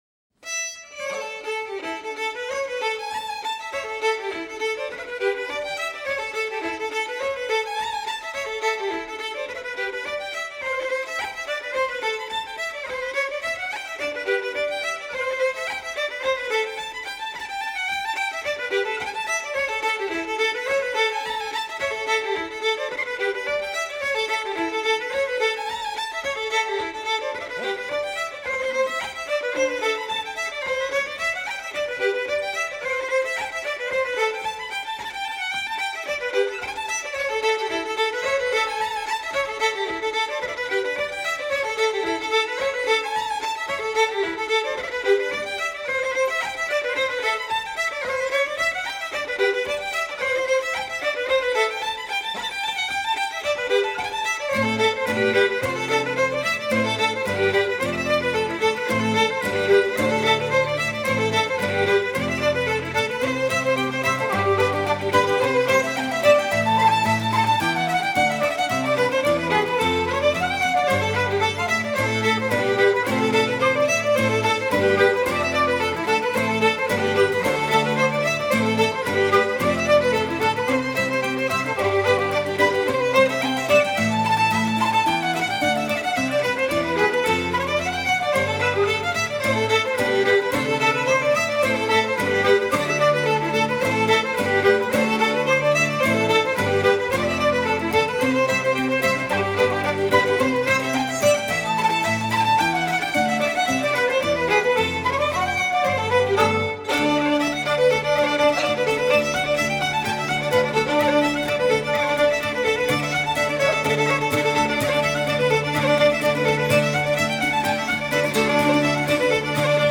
本专辑所包含的音乐比一般的爱尔兰民谣要古老得多。
风笛、小提琴等乐器逐渐发展出爱尔兰音乐的固有特色。
幸好，这是一张现场录音的演奏会唱片。